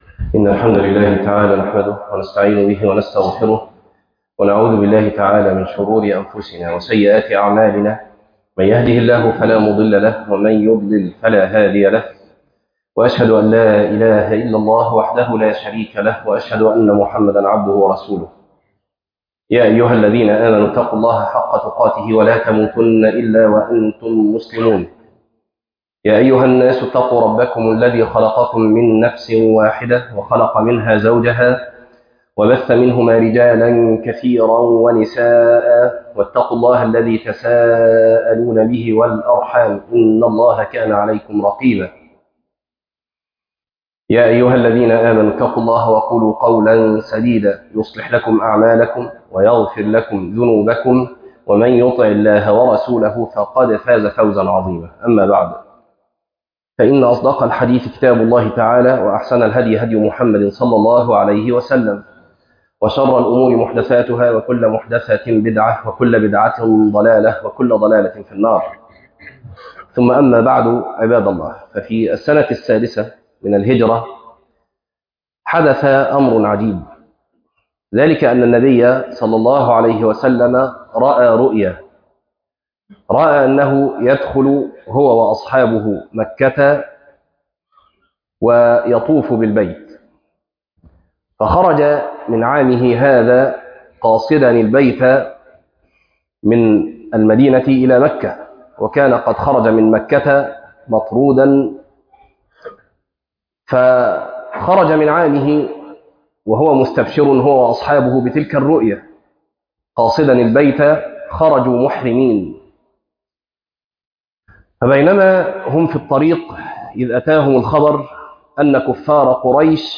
الصلــح - خطبة